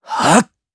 Neraxis-Vox_Casting1_jp.wav